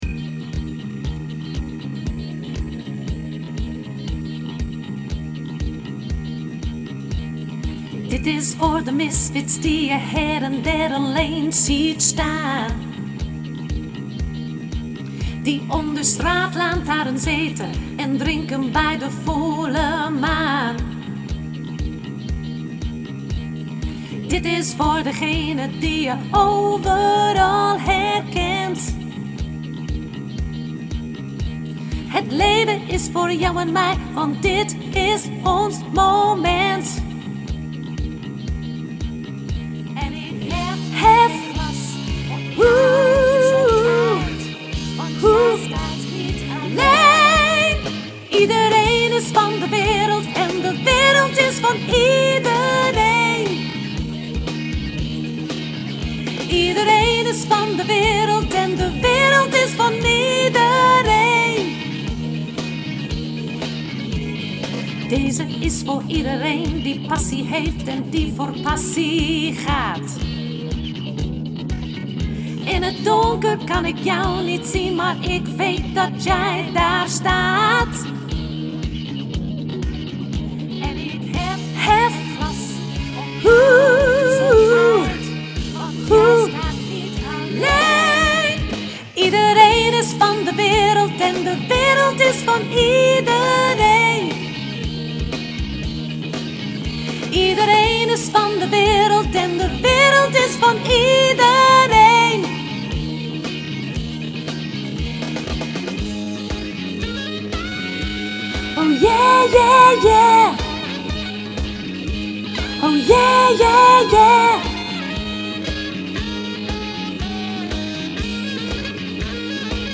sopraan hoog